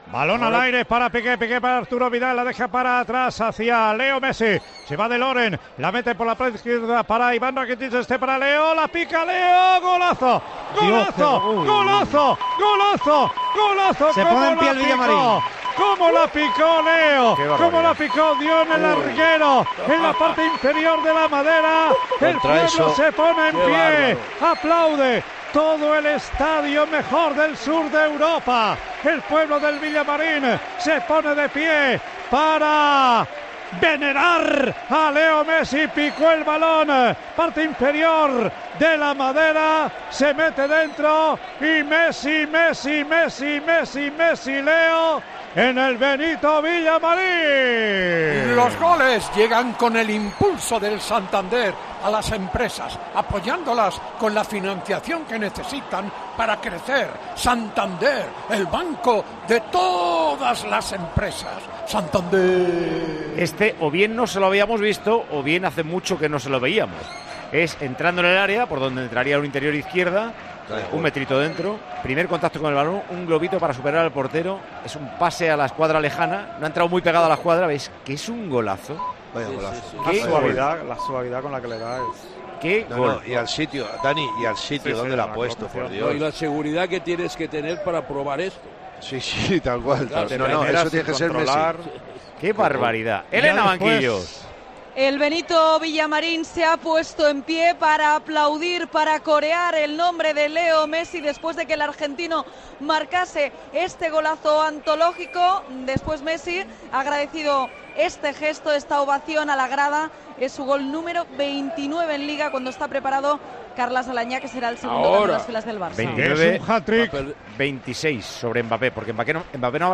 AUDIO: Messi la pica por encima de Pau con gran precisión, poniendo en pie al público del Villamarín.